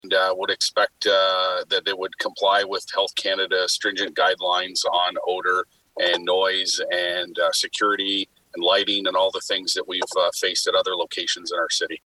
At its virtual meeting on Monday, City council approved the rezoning of part of the building at 665 Dundas Street East.
Chair of Planning Councillor Paul Carr told council  the issues had been dealt with.